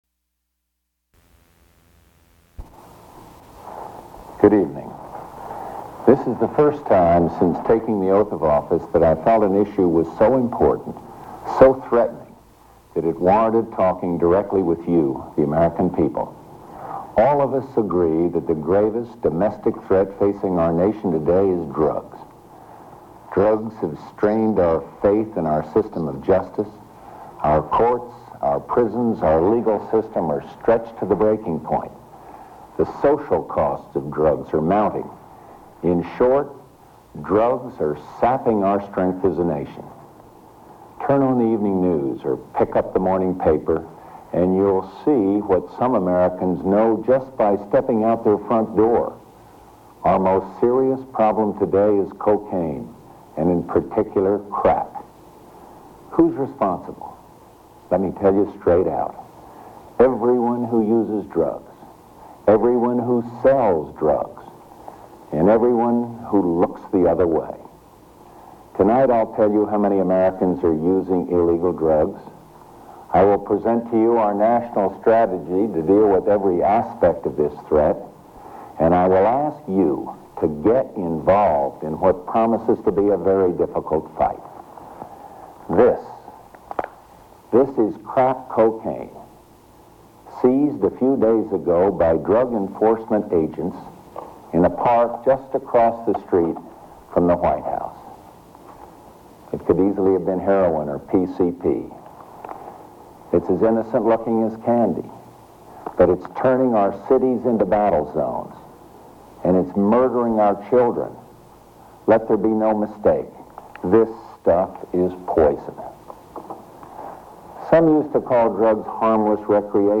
George Bush deals with drugs in his first presidential address from the Oval Office
Broadcast on CBS-TV, September 1989.